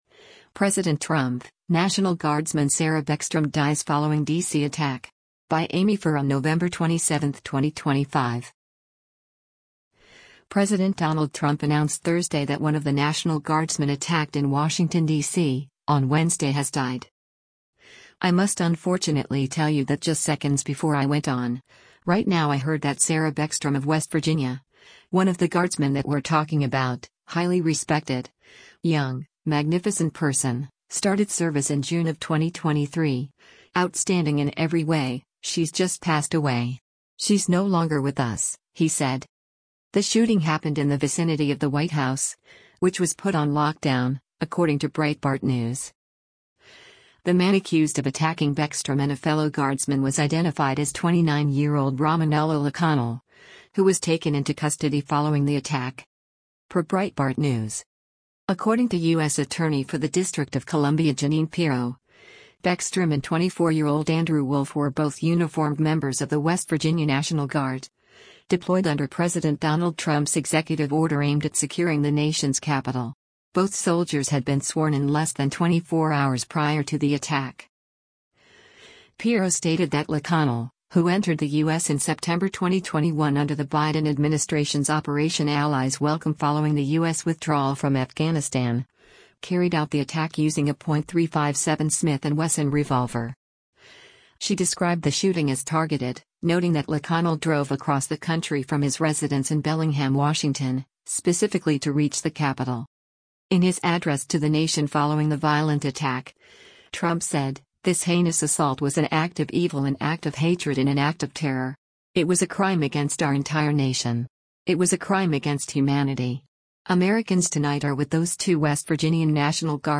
President Donald Trump announced Thursday that one of the National Guardsmen attacked in Washington, DC, on Wednesday has died.